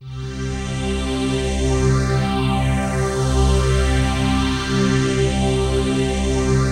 PHASEPAD36-LR.wav